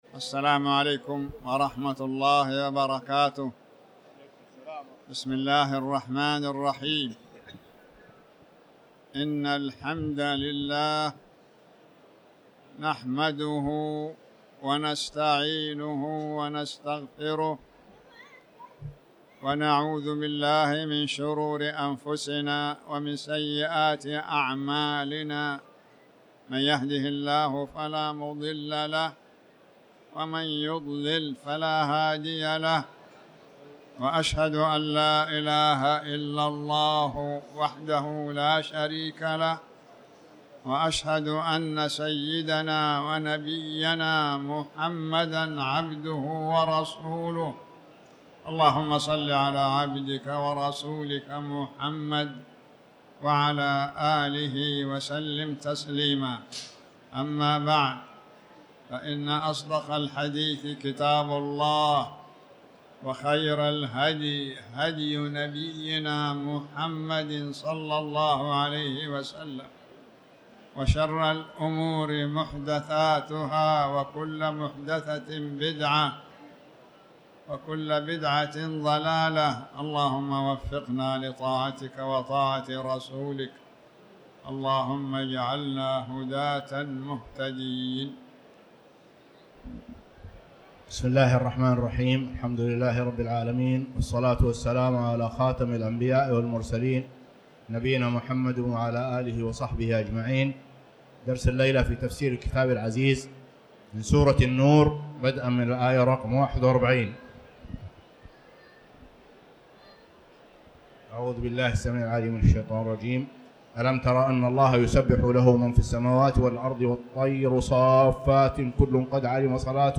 تاريخ النشر ٨ جمادى الأولى ١٤٤٠ هـ المكان: المسجد الحرام الشيخ